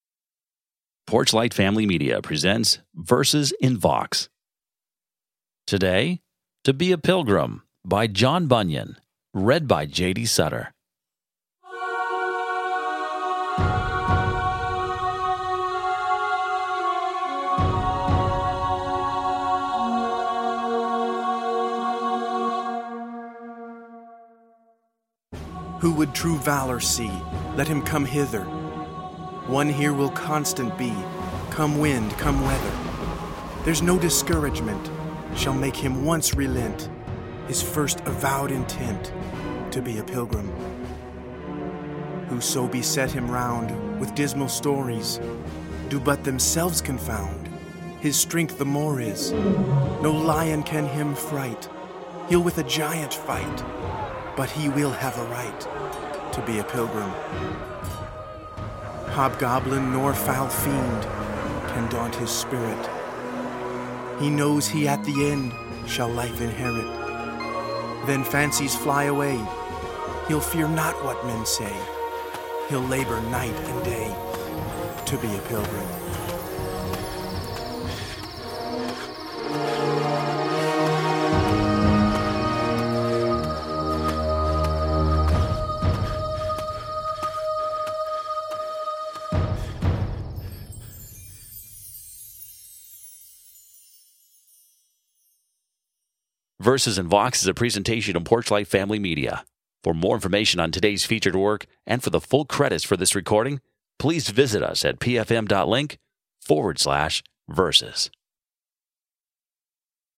Dramatic reading
with original music